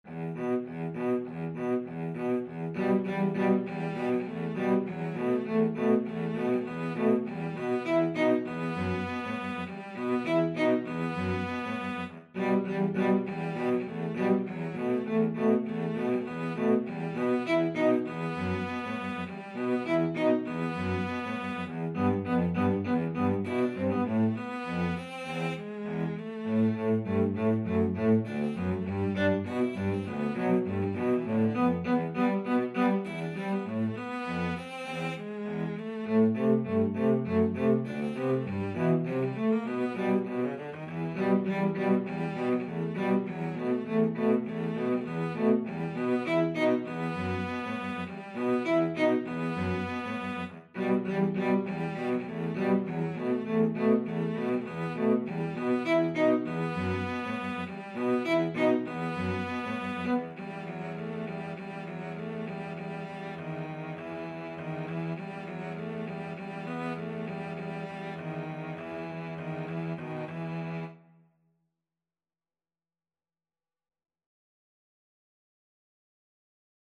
Free Sheet music for Cello Duet
E minor (Sounding Pitch) (View more E minor Music for Cello Duet )
Allegro Moderato (View more music marked Allegro)
Cello Duet  (View more Easy Cello Duet Music)
Classical (View more Classical Cello Duet Music)